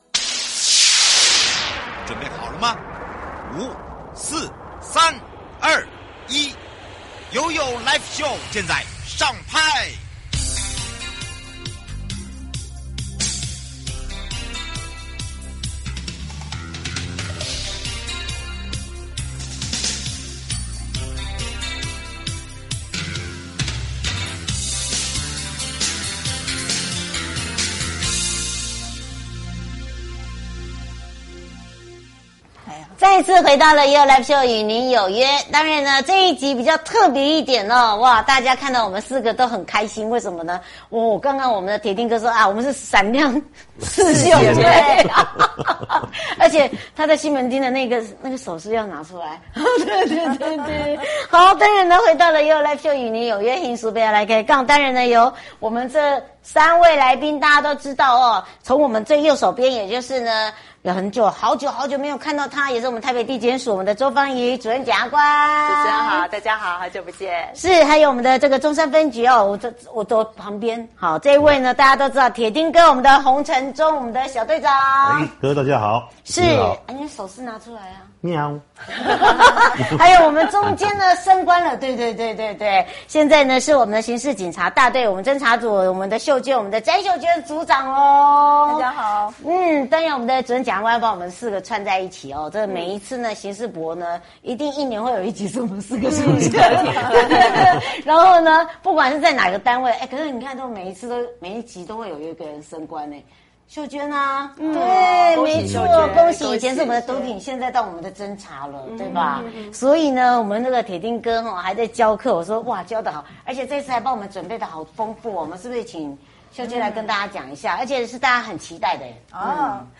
直播 主題：「從黑暗深淵伸出的魔手-淺談網路犯罪」